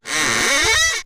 01DoorClose.ogg